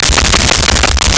The Main Reactor Loop 01
The Main Reactor Loop 01.wav